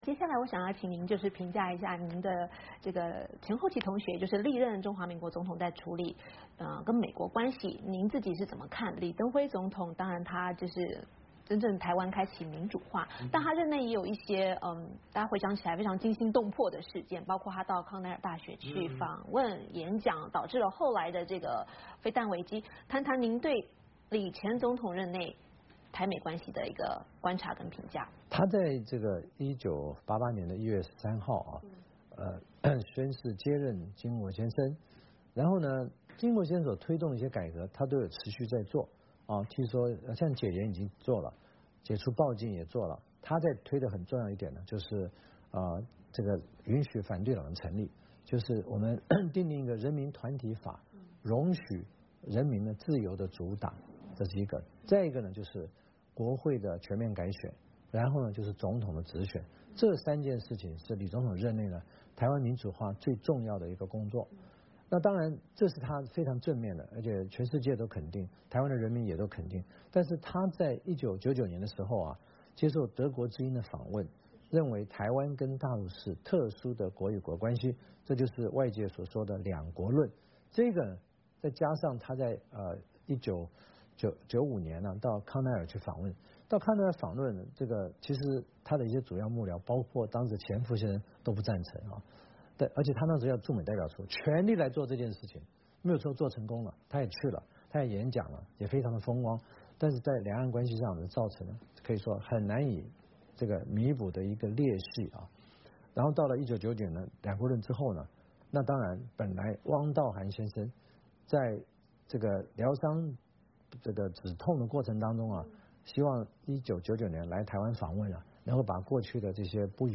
VOA专访: 前台湾总统马英九评前后任总统的对美外交
在美国与台湾断交40年之际，美国之音在台北专访了台湾各派政界领袖。